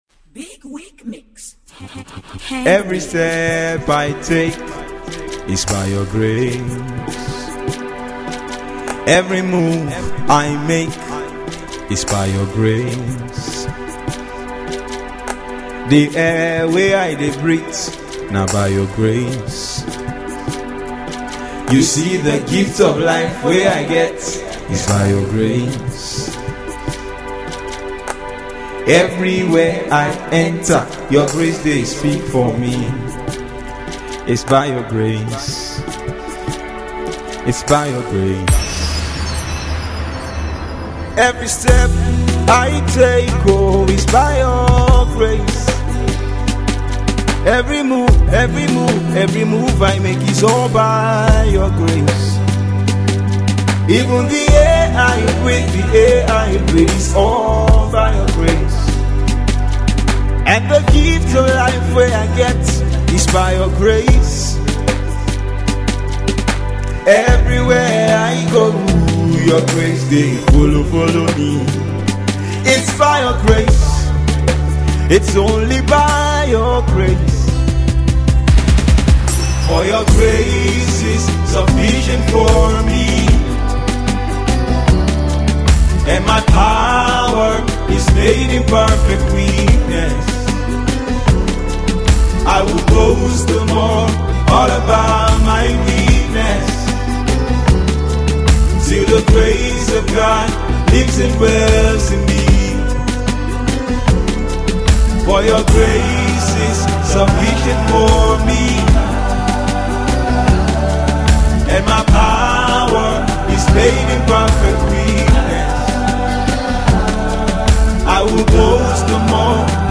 gospel music
a vocalist